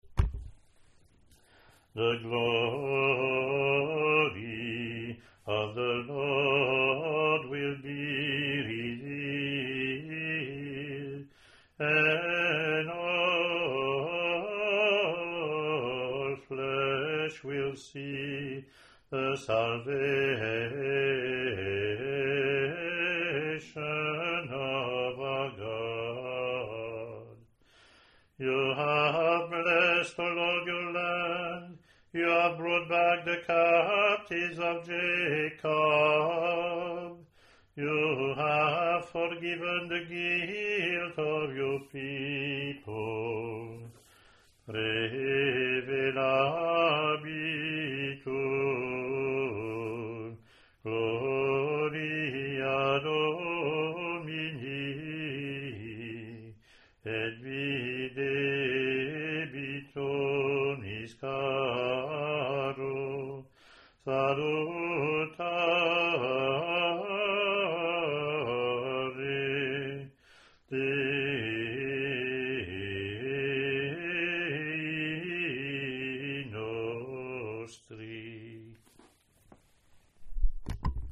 Click to hear Communion (
English antiphon – English verse – Latin antiphon + verses)